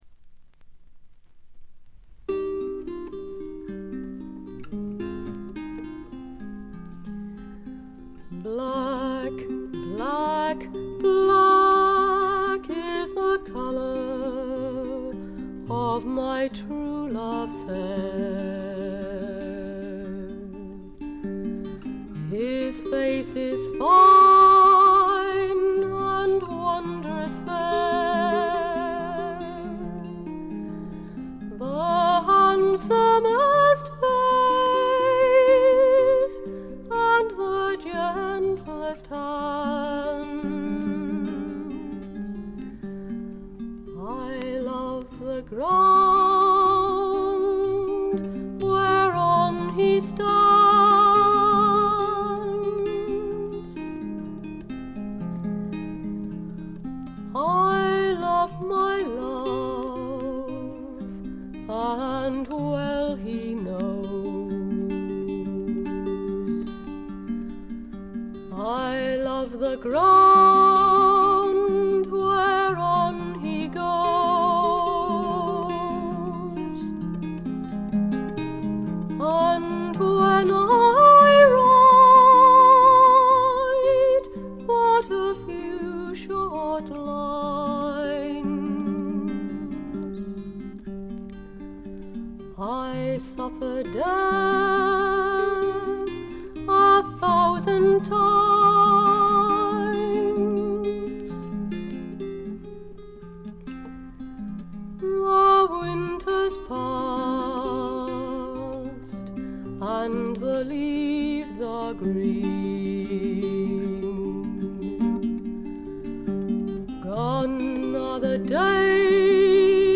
軽微なバックグラウンドノイズ、チリプチ。
試聴曲は現品からの取り込み音源です。